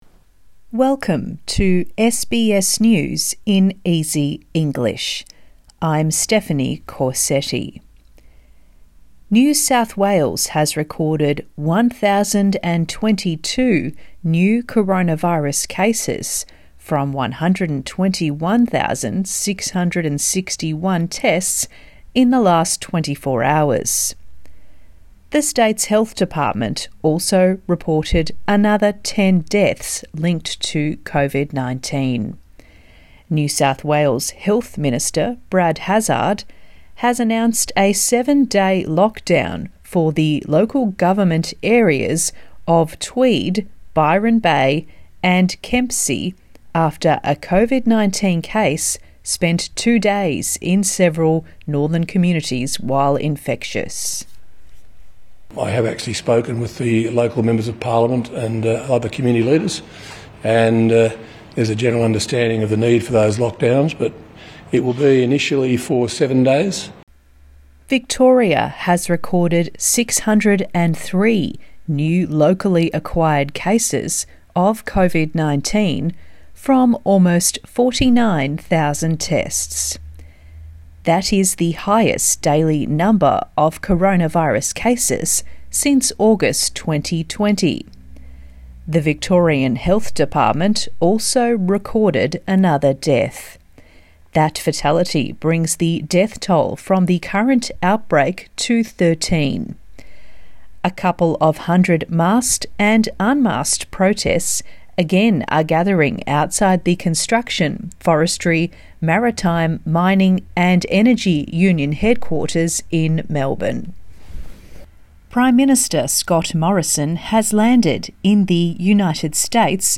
SBS News in Easy English bulletin 21 September 2021
A daily 5 minute news wrap for English learners